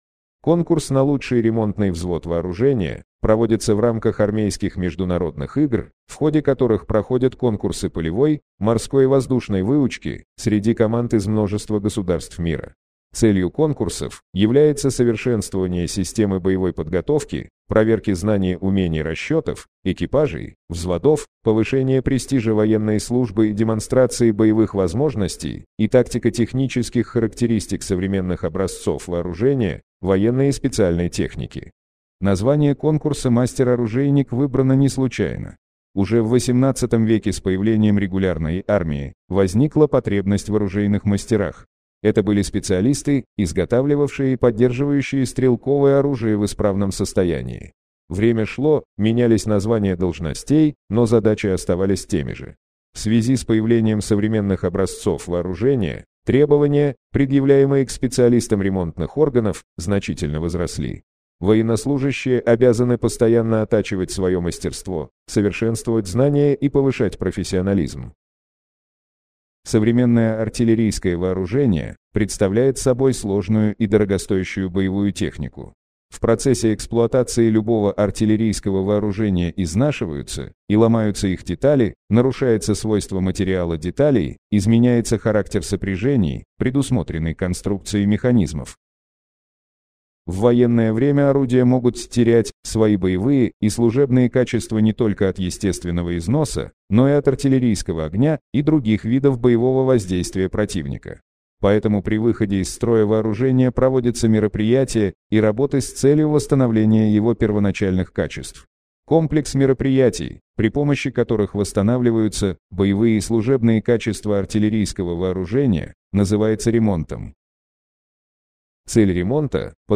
Аудио гид: